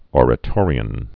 Or·a·to·ri·an
(ôrə-tôrē-ən, ŏr-)